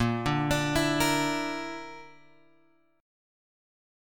A# Major Flat 5th